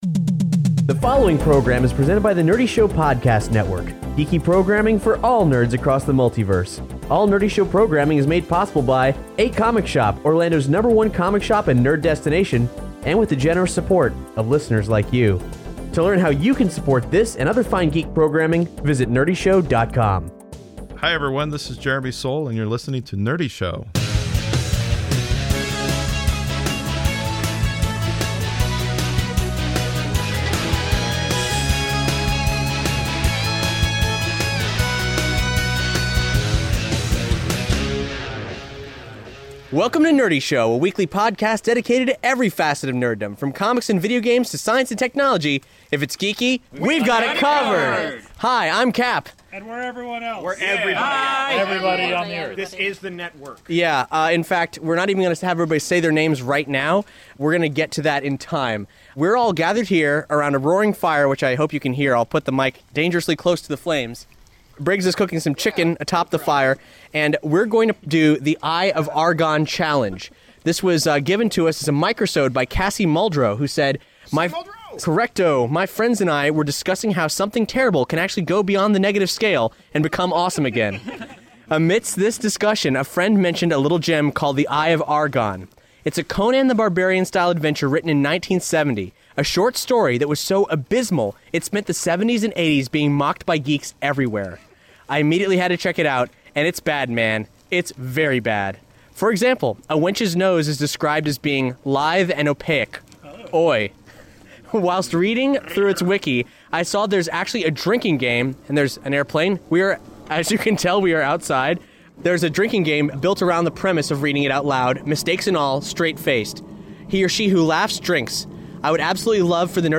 Join hosts from across The Nerdy Show Network around a roaring winter fire as they drink a toast to one of the most celebrated monstrosities in nerd literature.